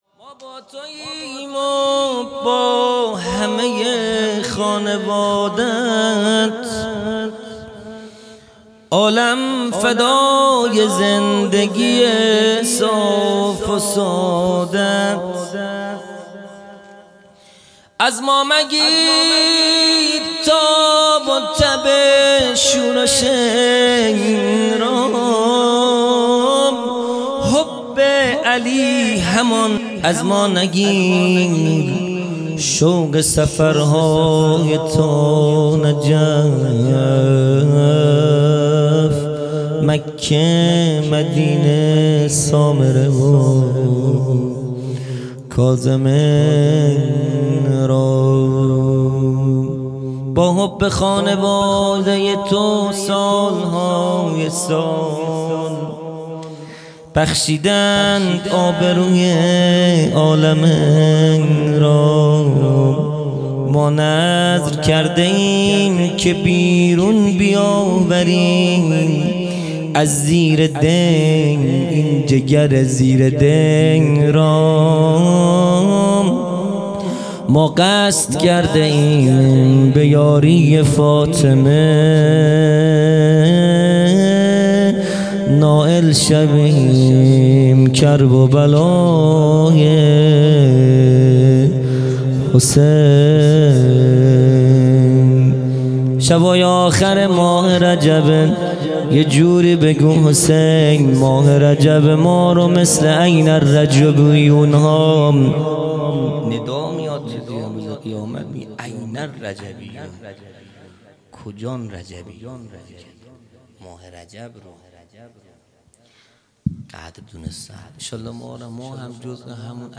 خیمه گاه - هیئت بچه های فاطمه (س) - مدح | ما با تو ایم و با همۀ خانواده ات
جلسۀ هفتگی ( به مناسبت مبعث رسول اکرم(ص))